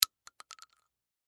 Звуки дробовика
Звук упавшей гильзы от дробовика на пол (пустой, использованный)